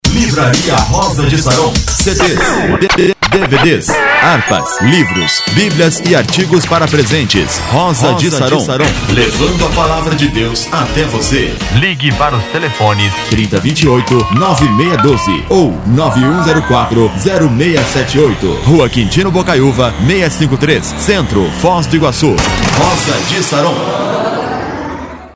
Masculino
Voz Padrão - Grave